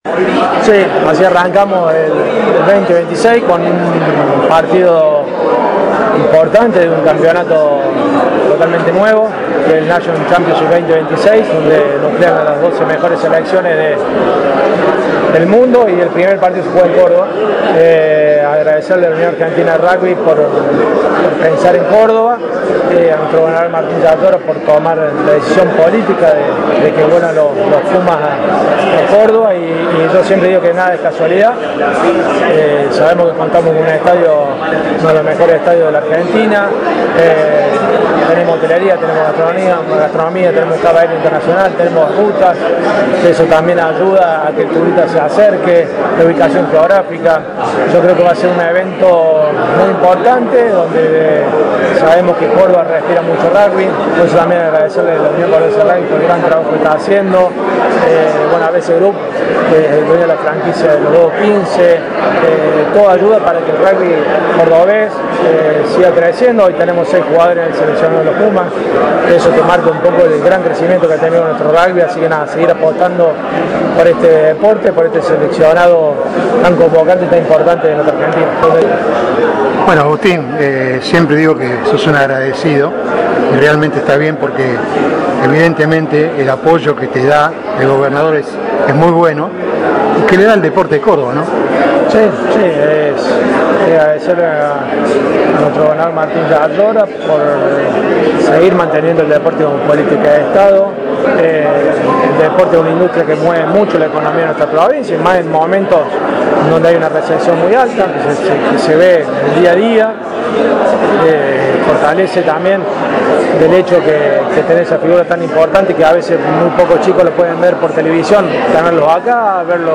Audio de la nota con AGUSTÍN CALLERI: